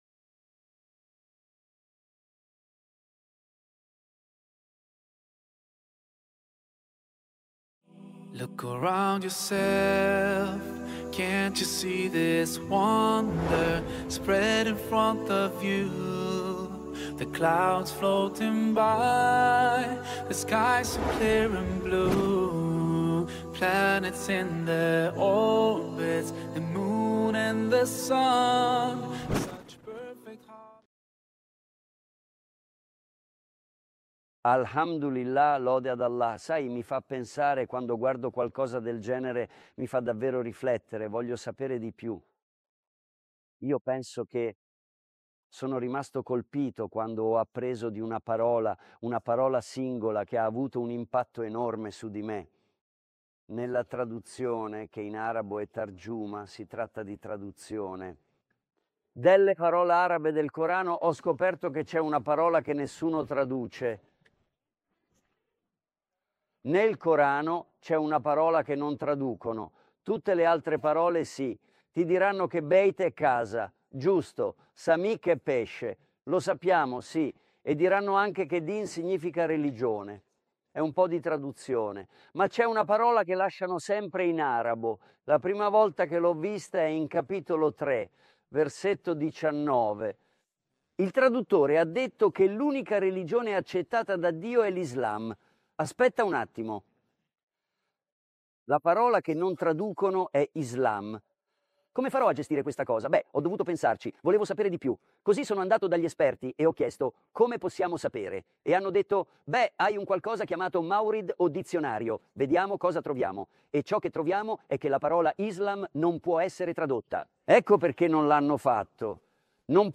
girata tra i paesaggi mozzafiato e i luoghi storici della Giordania. In questo episodio, spiega cosa significa Islam.